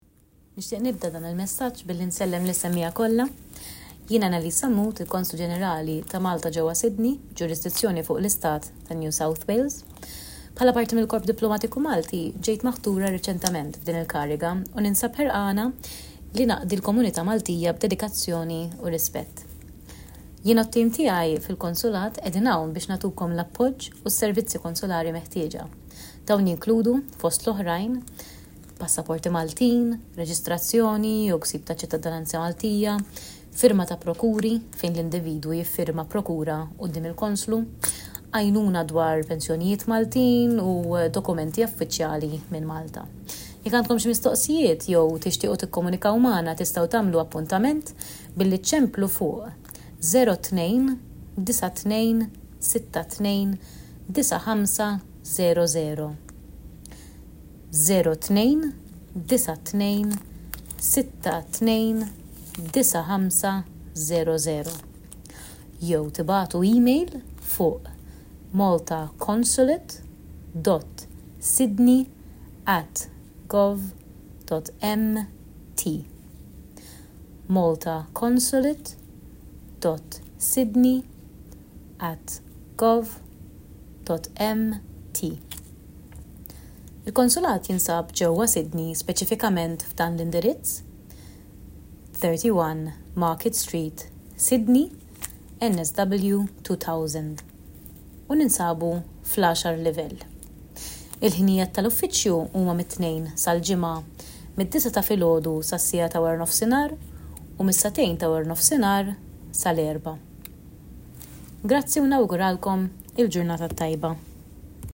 Messaġġ ta’ merħba mill-Konslu Ġenerali ġdid ta’ Malta fi NSW Ms Anneliese Sammut
F’messaġġ lill-komunità Maltija ta’ New South Wales, il-Konslu Ġenerali ġdid ta’ Malta, fi New South Wales, Ms Anneliese Sammut titkellem dwar il-kariga ġdida tagħha u s-servizzi li joffri l-Konsulat Malti fi New South Wales.